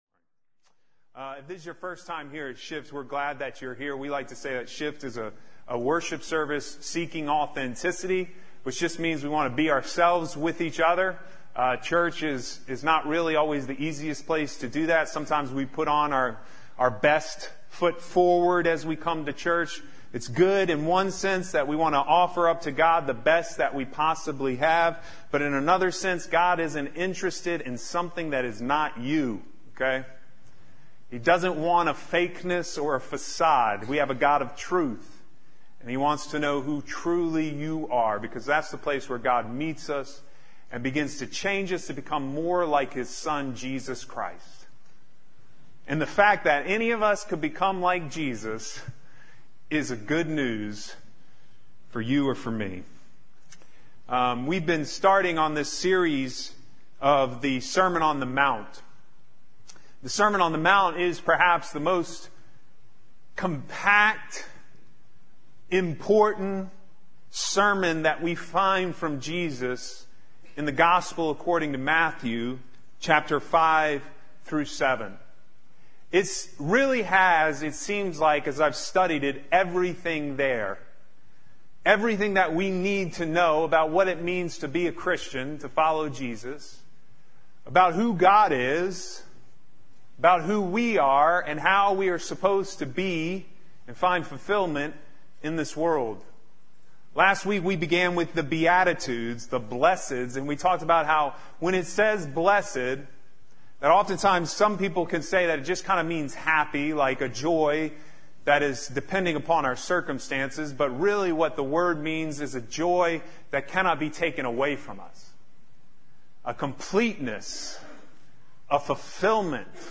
Shift-Sermon-8-26-18.mp3